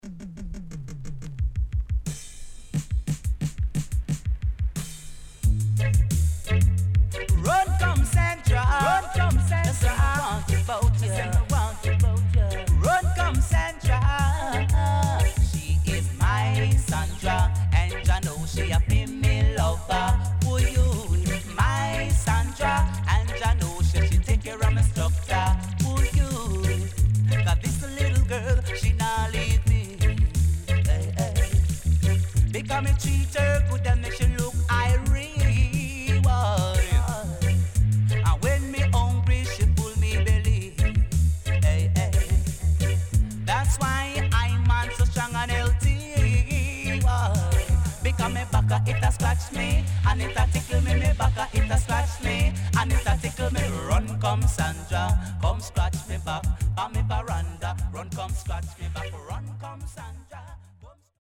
HOME > DISCO45 [DANCEHALL]
Digital Dancehall
SIDE A:少しチリノイズ入りますが良好です。